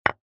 capture.mp3